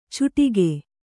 ♪ cuṭige